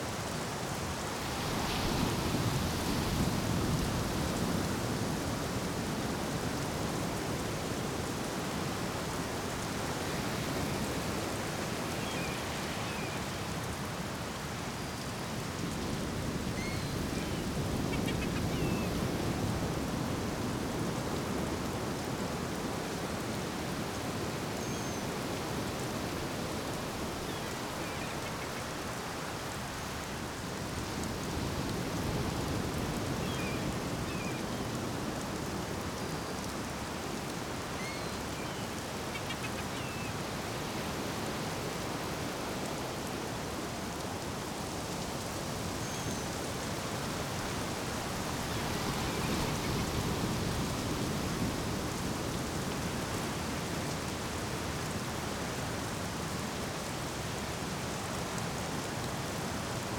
Beach Rain.ogg